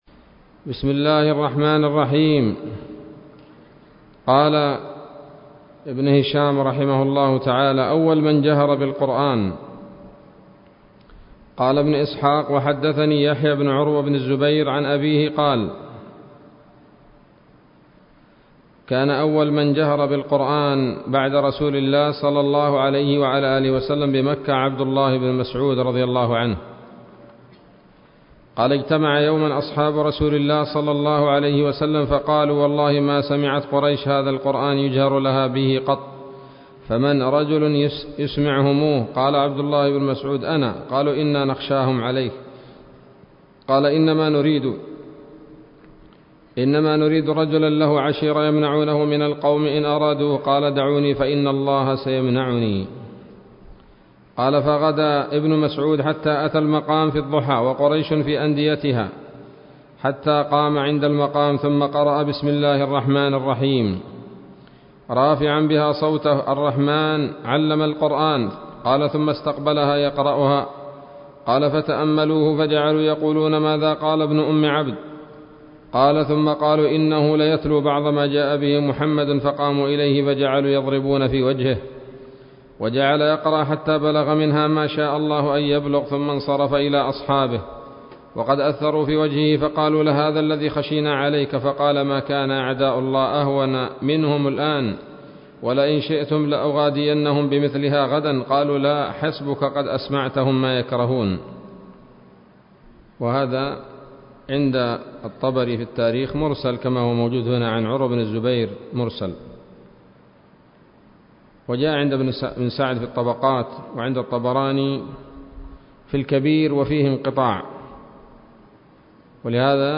الدرس الثالث والثلاثون من التعليق على كتاب السيرة النبوية لابن هشام